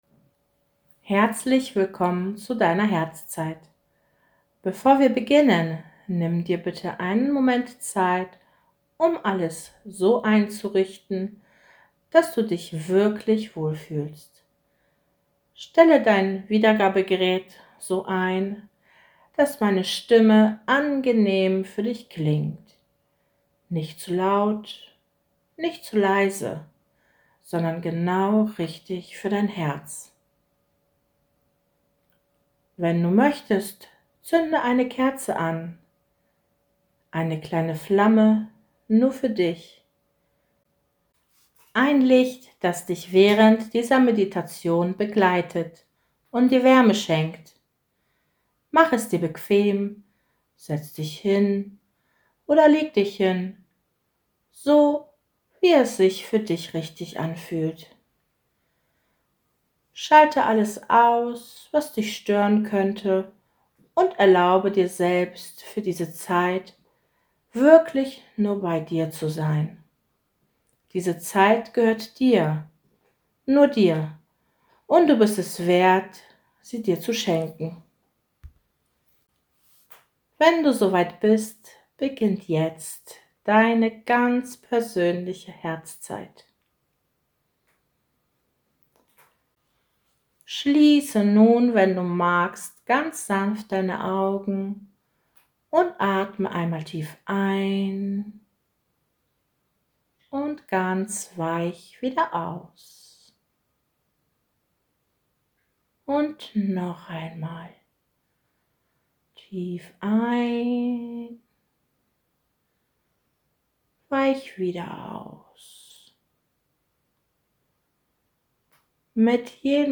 🩷 Geführte Meditation Download